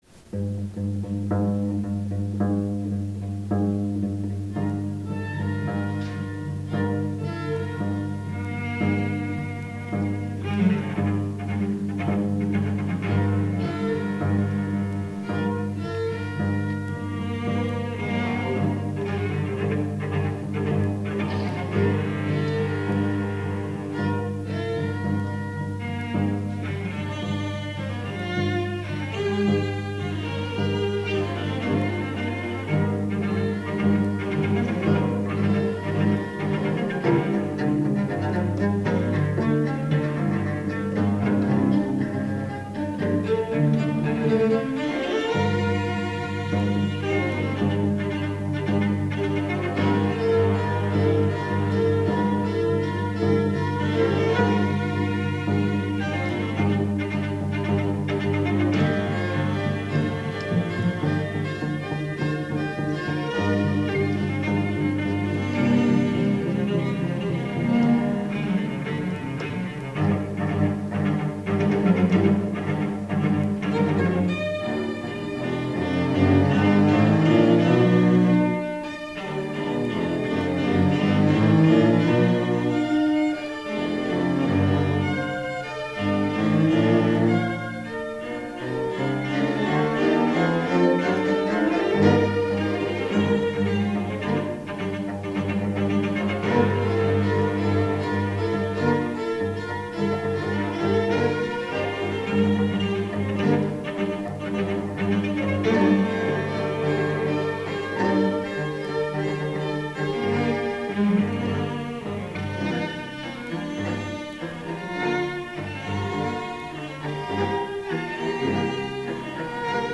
for Six Celli (1998)